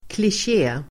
Uttal: [klisj'e:]